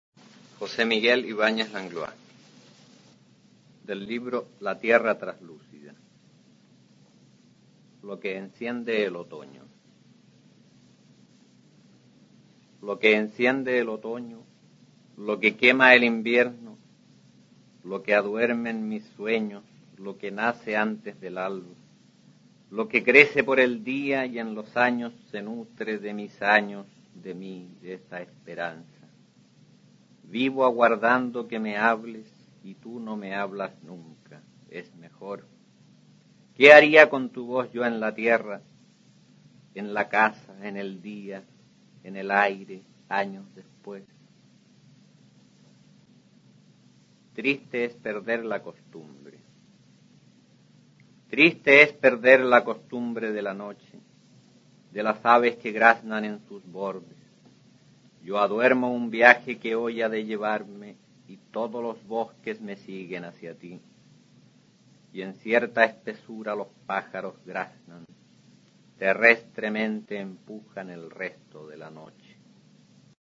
Aquí se puede escuchar al autor chileno José Miguel Ibáñez Langlois recitando sus poemas Lo que enciende el otoñoy Triste es perder la costumbre, del libro "La tierra traslúcida" (1958).